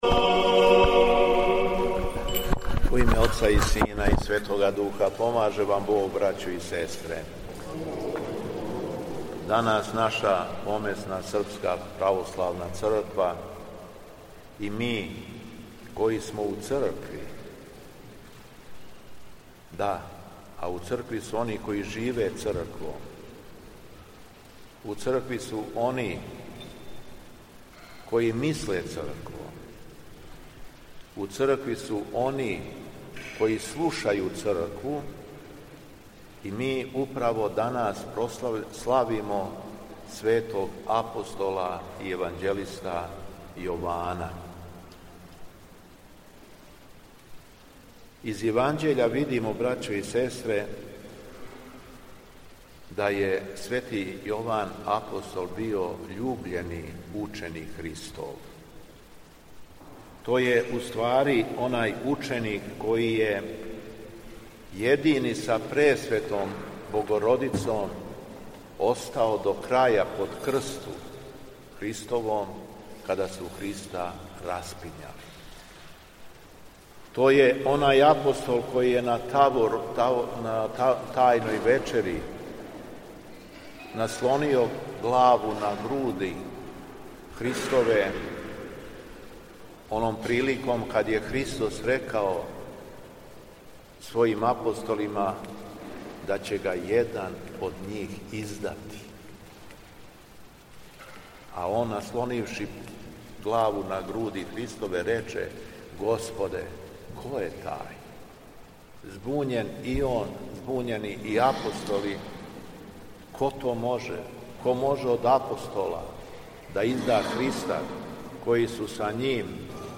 Беседа Његовог Високопреосвештенства Митрополита шумадијског г. Јована
Поводом 91. година од мученичке смрти Краља Александра I Карађорђевића у храму Светог Великомученика Георгија на Опленцу, 9. октобра 2025. године, Његово Високопреосвештенство Митрополит шумадијски Господин Јован служио је Свету архијерејску Литургију и парастос Краљу Александру I Карађорђевићу.